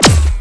fire_pulsar_cannon.wav